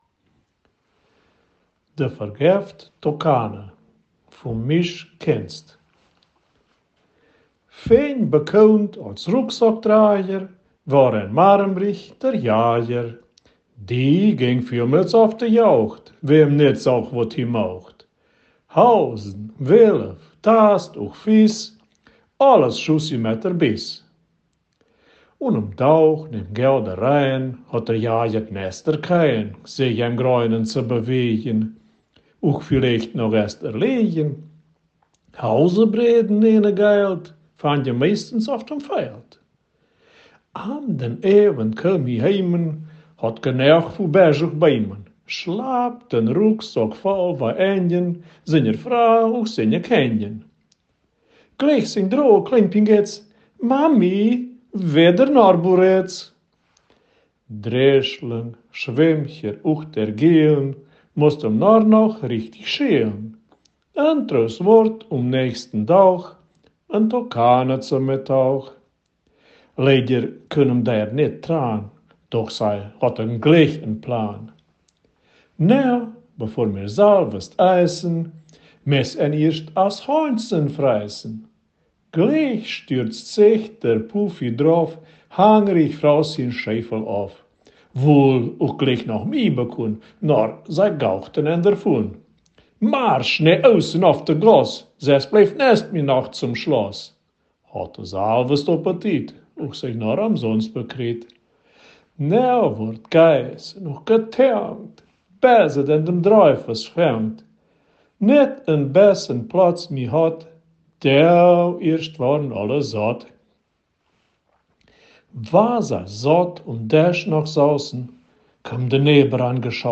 Ortsmundart: Marienburg bei Schäßburg